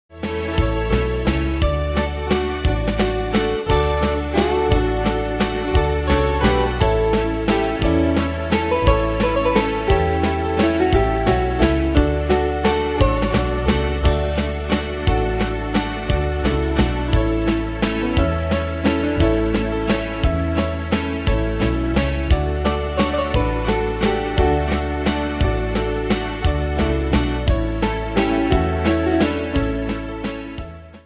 Pro Backing Tracks